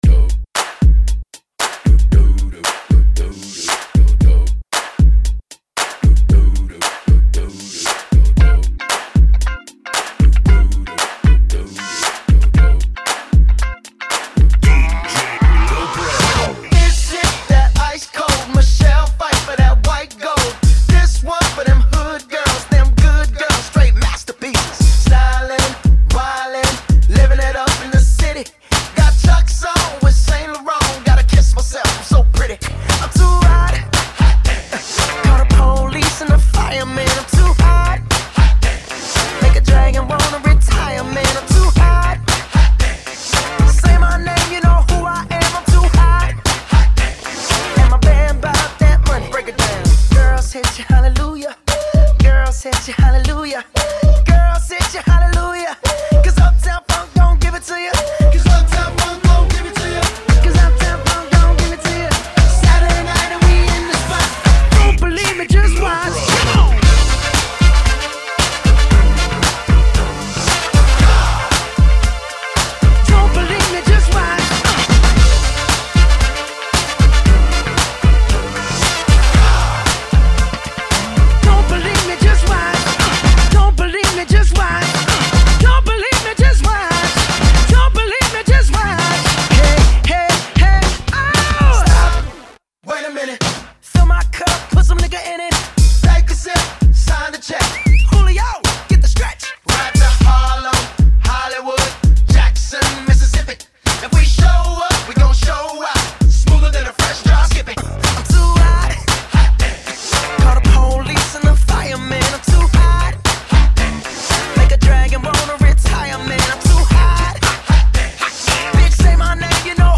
[ 115 bpm ] FunKy